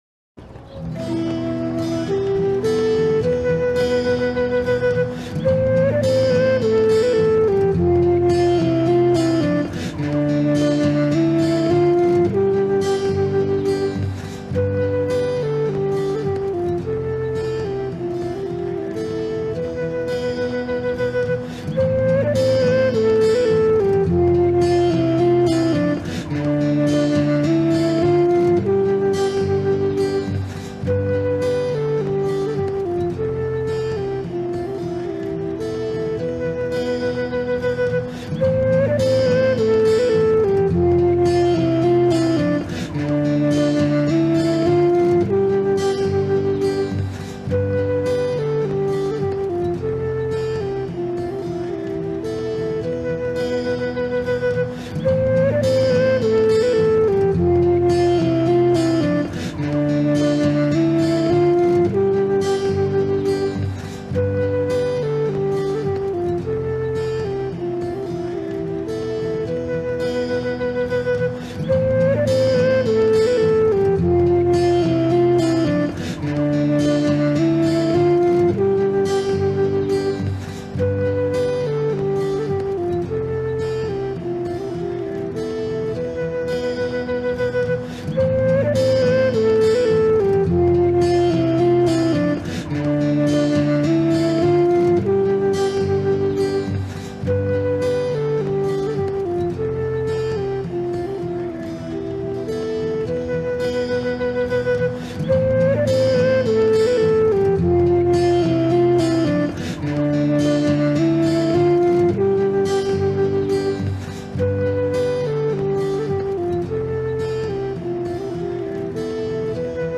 Fon Müziği